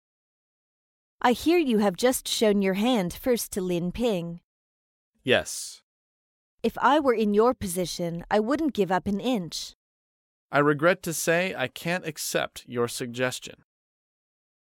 在线英语听力室高频英语口语对话 第148期:拒绝严厉处事建议的听力文件下载,《高频英语口语对话》栏目包含了日常生活中经常使用的英语情景对话，是学习英语口语，能够帮助英语爱好者在听英语对话的过程中，积累英语口语习语知识，提高英语听说水平，并通过栏目中的中英文字幕和音频MP3文件，提高英语语感。